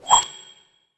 nut_fly_01.ogg